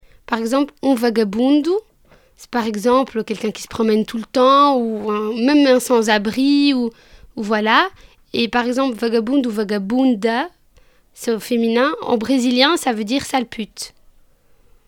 prononciation Vagabundo ↘ explication Par exemple, un vagabundu, c’est quelqu’un qui se promène tout le temps, ou… même un sans-abri.